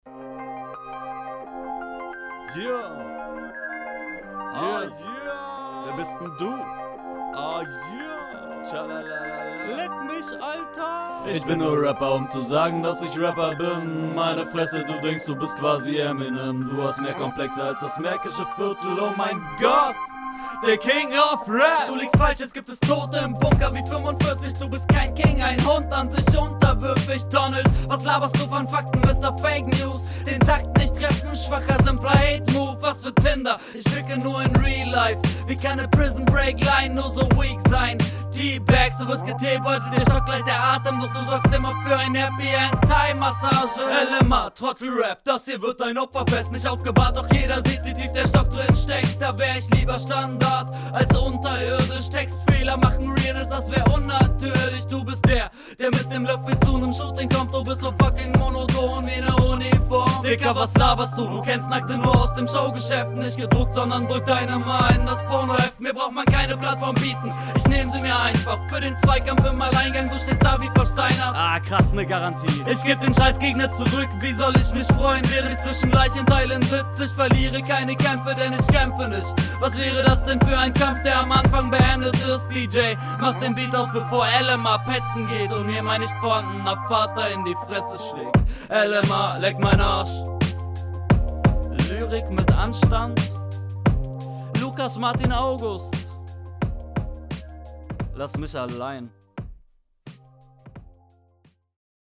Sehr interessanter Flow, hört sich ziemlich cool an.
Viel mehr Energie und die Bunkerline super gekontert, so geht Einstieg!
Deine Doubles sind zu laut …